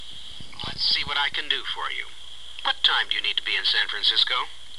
英語發音 English Pronunciation
(加連線者為連音，加網底者不需唸出聲或音很弱。)